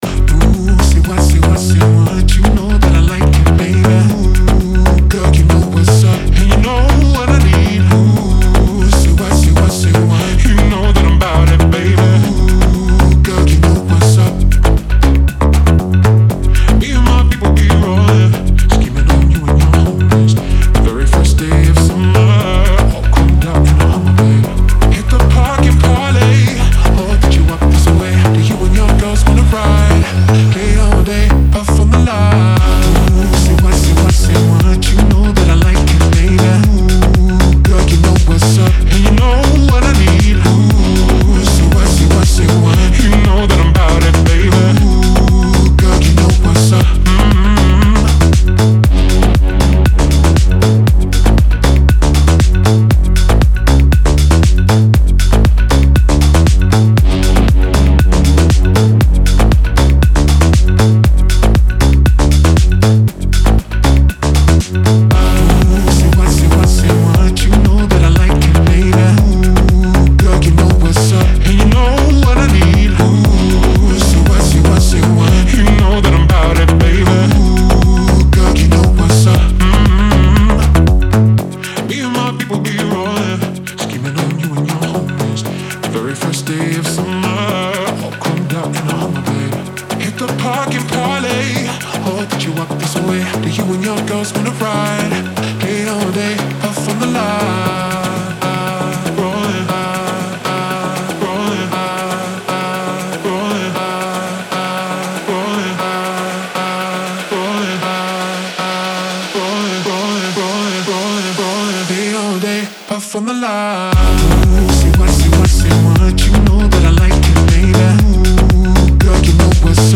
Звучание отличается яркими синтезаторами и ритмичными битами